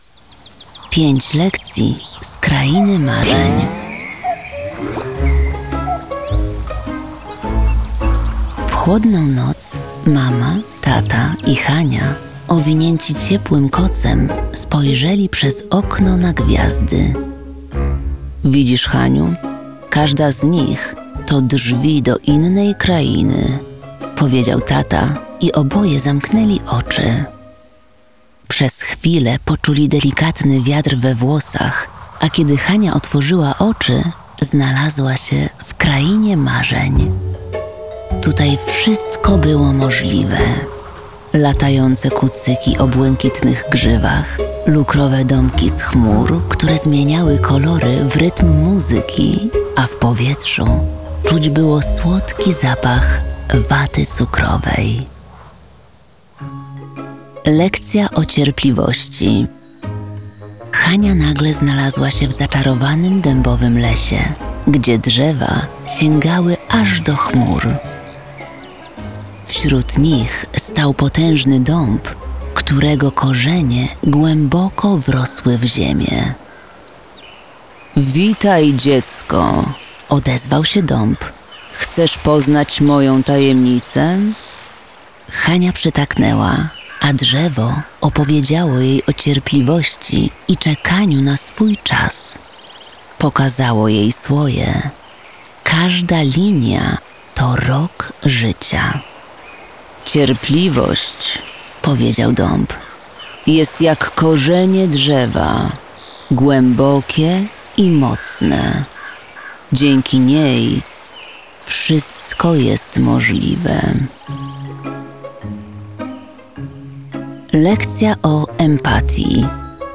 Bajka
Bajkę czyta Magdalena Różczka.
5-lekcji-z-krainy-marzen-lektor.wav